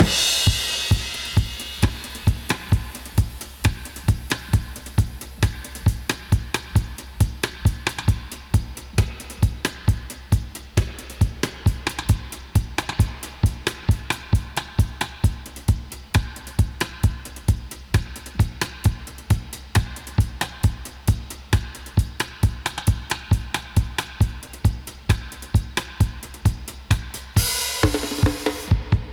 131-DUB-02.wav